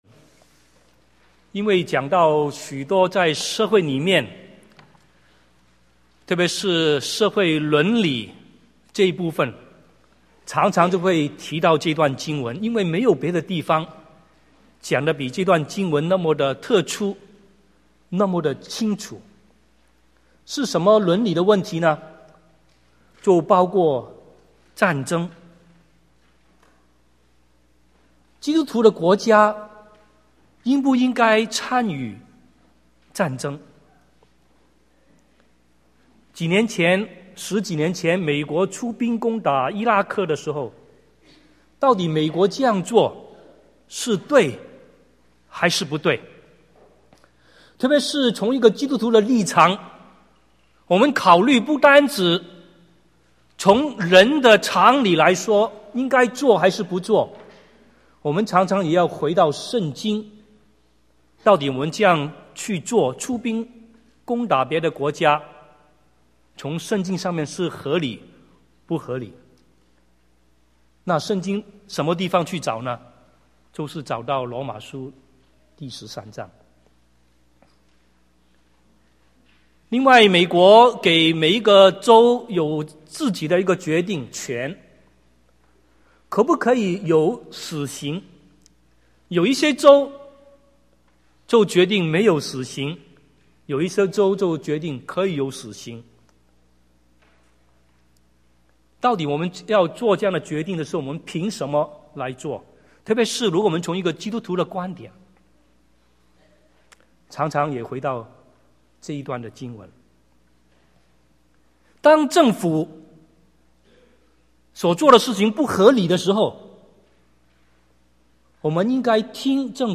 Sermon | CBCGB